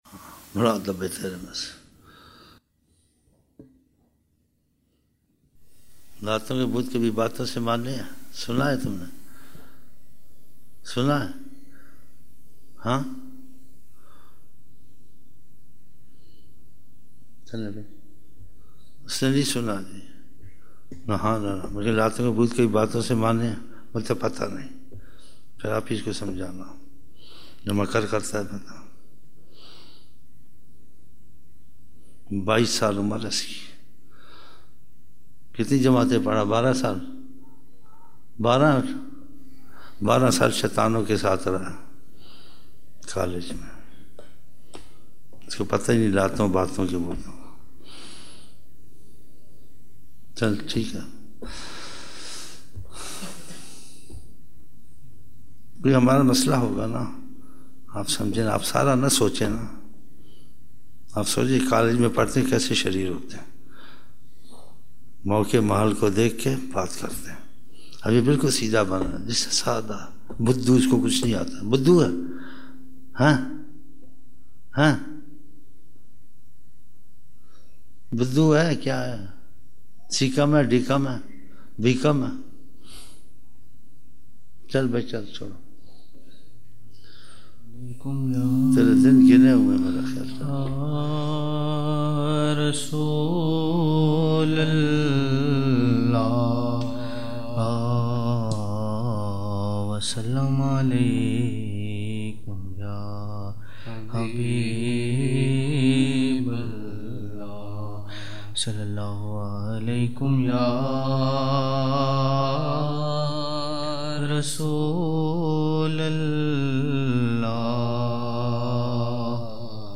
16 November 1999 - Assar mehfil (8 Shaban 1420)